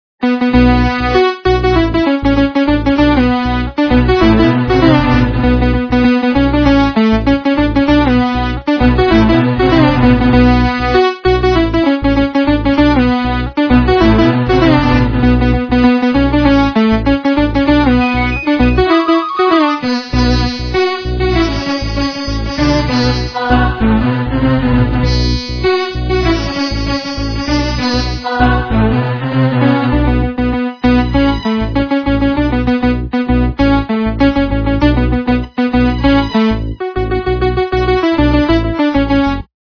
- западная эстрада